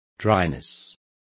Complete with pronunciation of the translation of dryness.